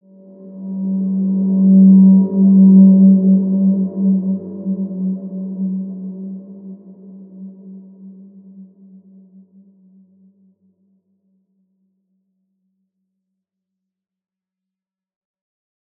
Large-Space-G3-p.wav